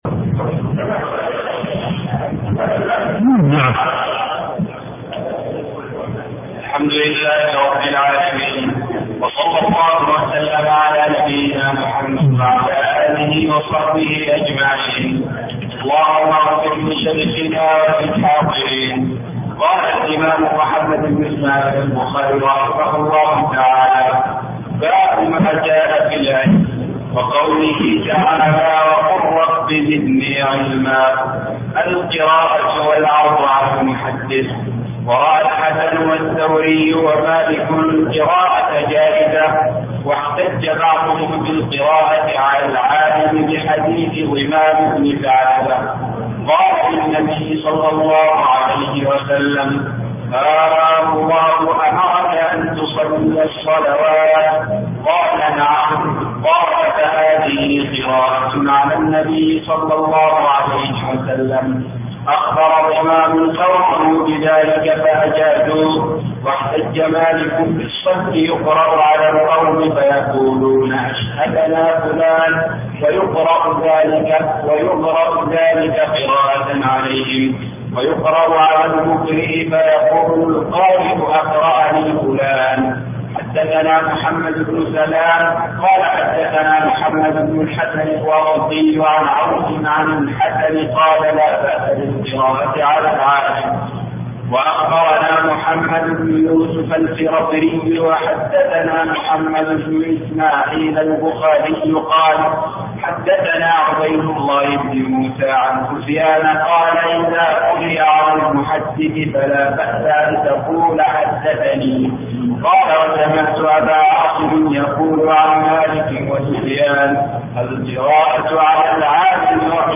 الدروس الشرعية
جامع البلوي